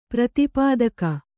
♪ prati pādaka